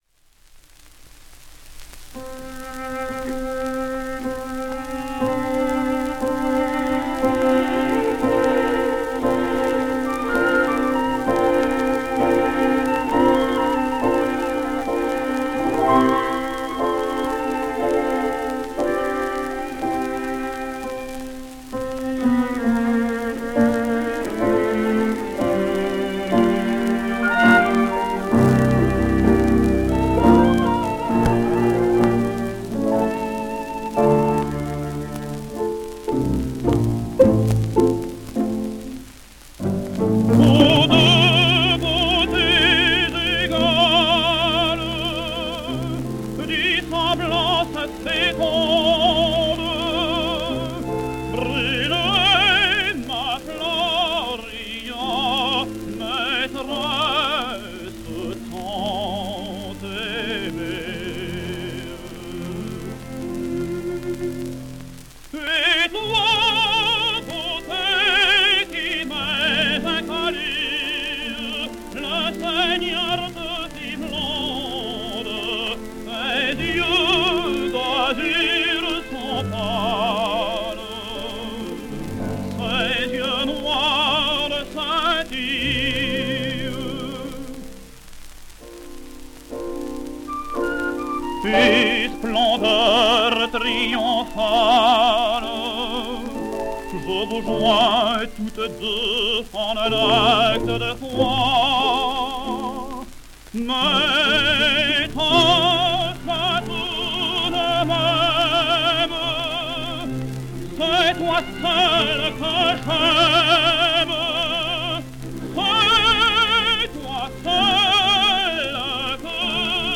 A record tenor?